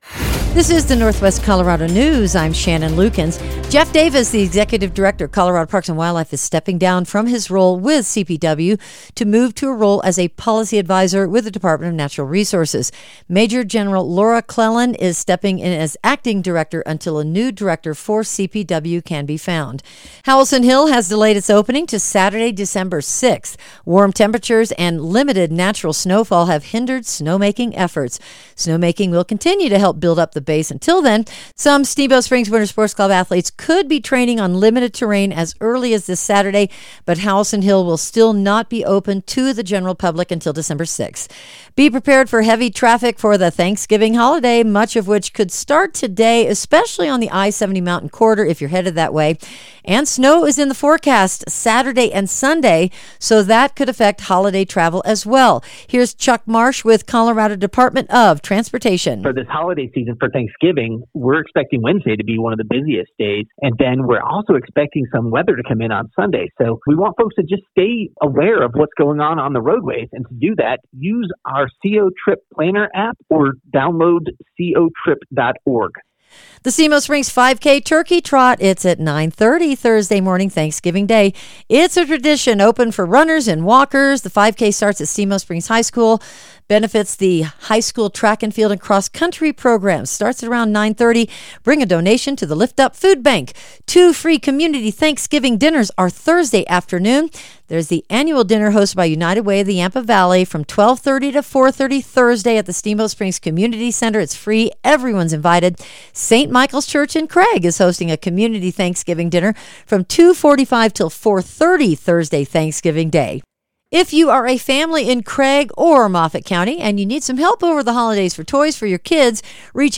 Northwest Colorado News for Wednesday, Nov. 26, 2025